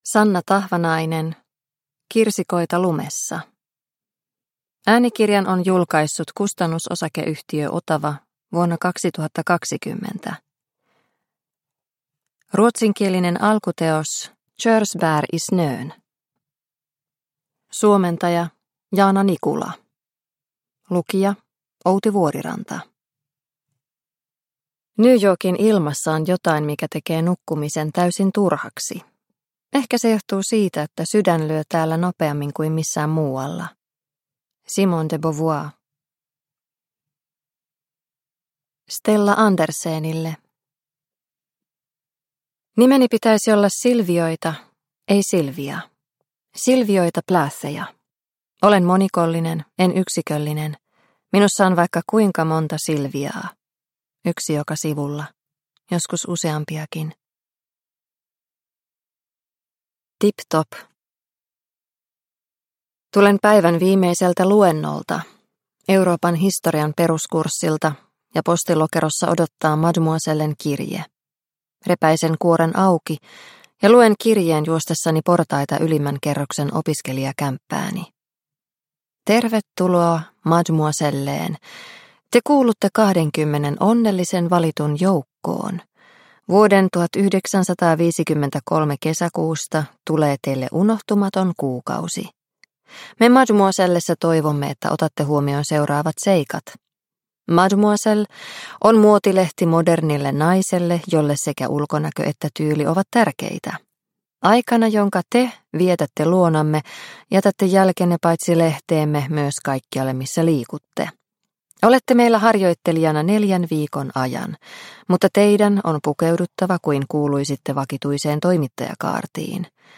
Kirsikoita lumessa – Ljudbok – Laddas ner